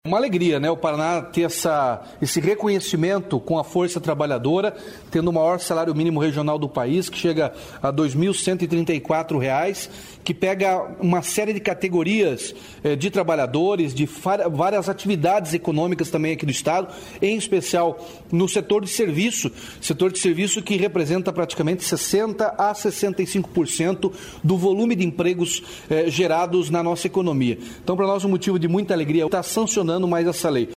Sonora do governador Ratinho Junior sobre o novo piso regional